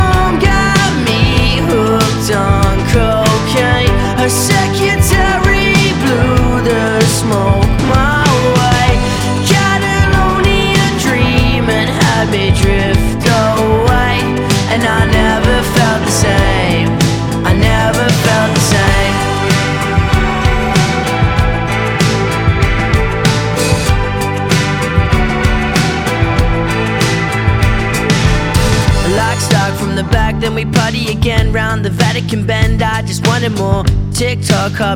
Нестандартные аккорды и неожиданные переходы трека
Жанр: Альтернатива